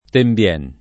Tembien [ temb L$ n ]